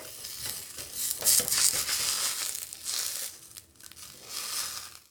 action_cook_0.ogg